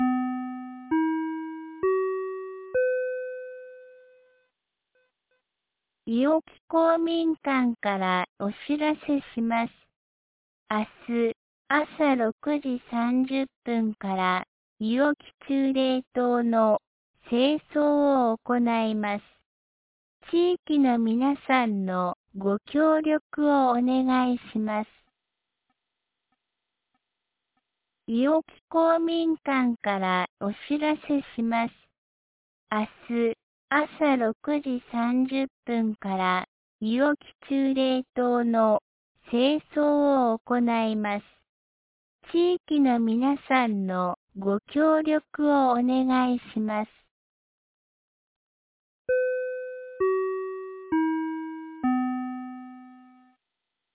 2025年07月25日 17時11分に、安芸市より伊尾木へ放送がありました。